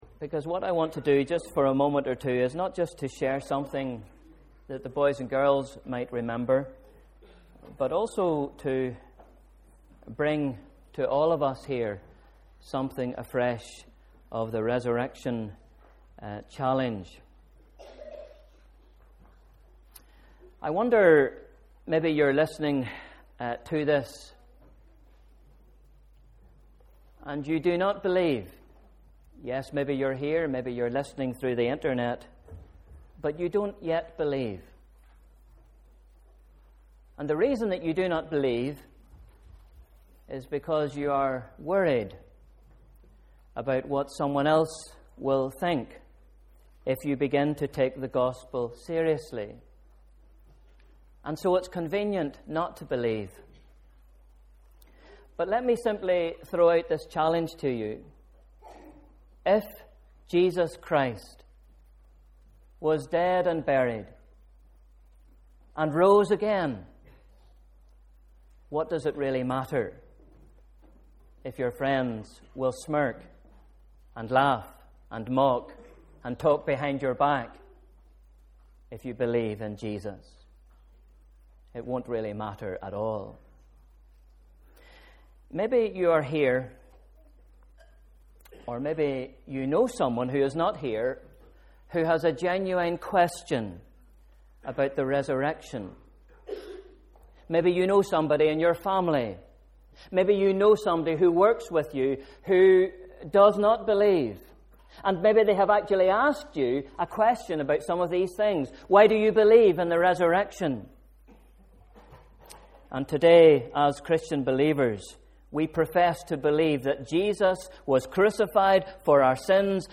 Sunday Morning Service – Easter Day (31st March 2013)